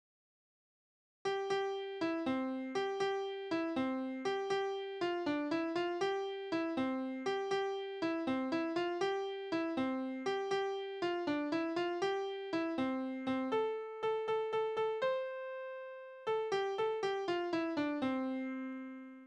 Kinderspiele: Tauben und Taubenhaus
Tonart: C-Dur
Taktart: 6/8
Tonumfang: Oktave
Anmerkung: - letzter Ton könnte auch d' sein